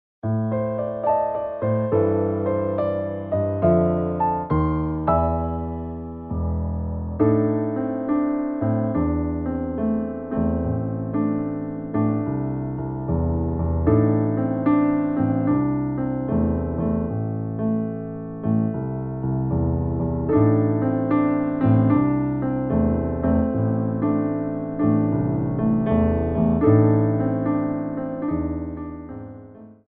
Piano Arrangements of Pop & Rock for Tap Class
SLOW TEMPO